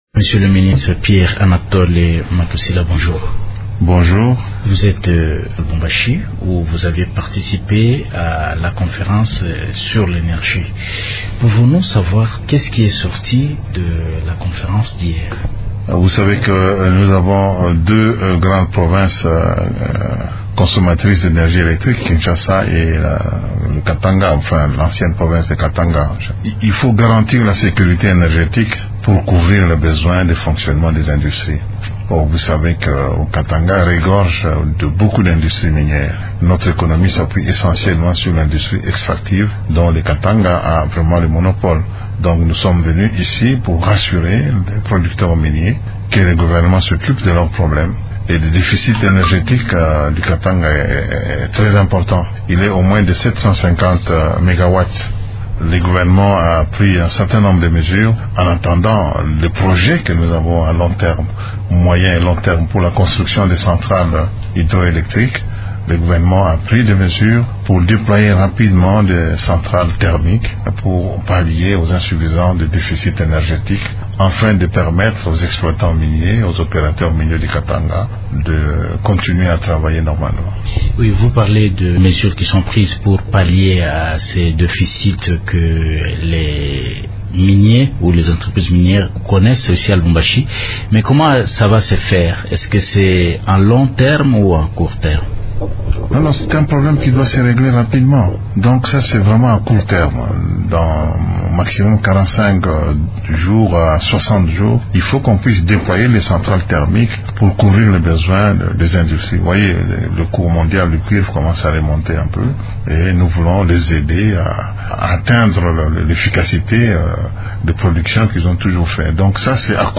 Le ministre de l’Energie, Pierre Anatole  Matusila, est l’invité de Radio Okapi. Il parle du projet du gouvernement congolais de mettre en place des centrales thermiques pour pallier le problème de déficit énergétique qui se pose en RDC, notamment dans les provinces issues du Katanga où de nombreuses entreprises minières sont installées.